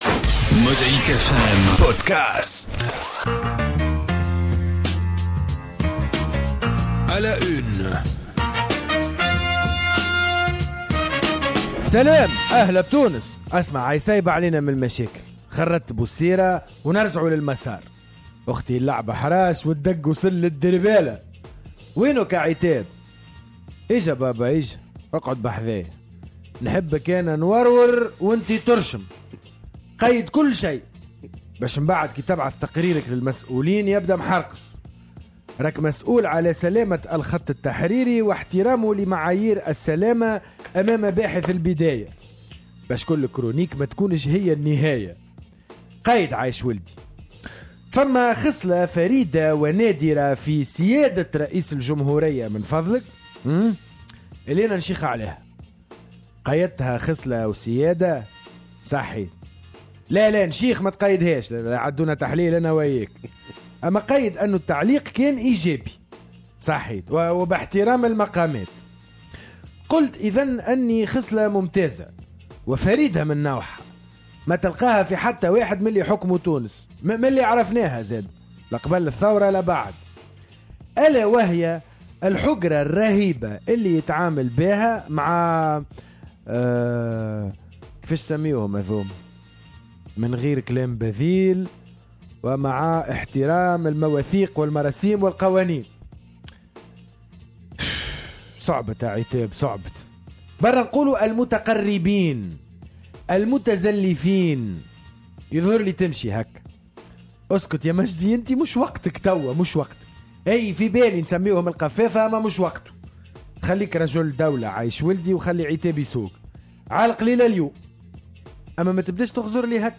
قراءة ساخرة في أبرز العناوين الصحفية اليومية والأسبوعية